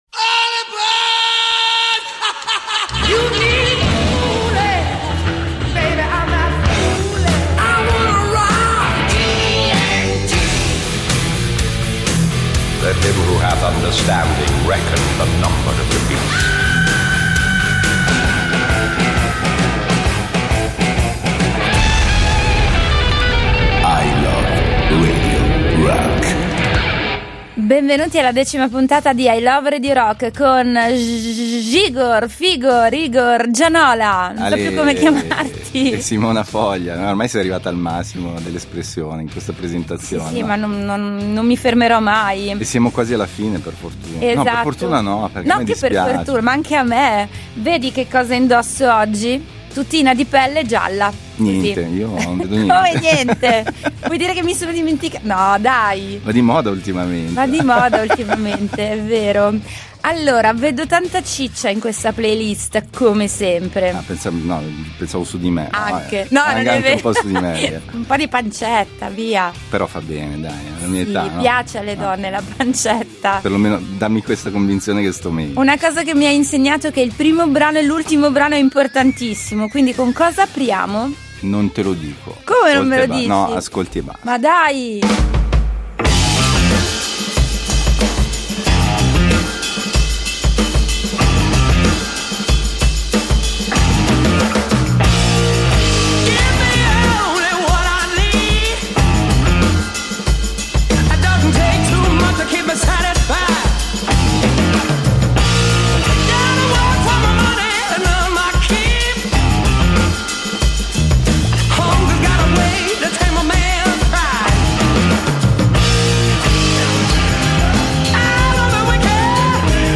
il programma dedicato alle sonorità hard rock e heavy metal che hanno fatto la storia. https